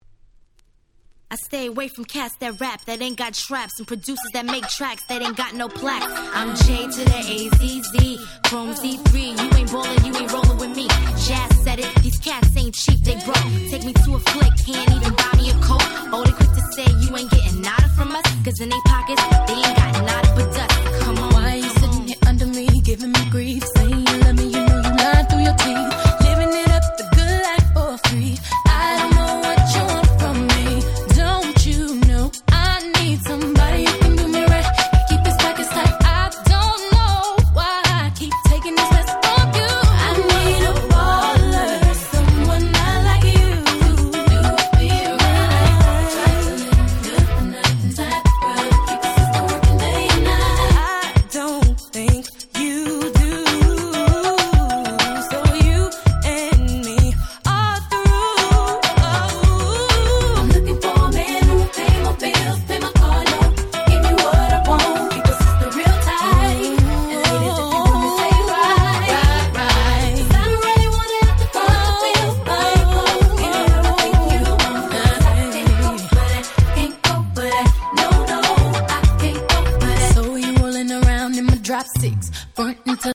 99' Super Hit R&B !!
ぐっと感じの変わったRemixも悪くないです！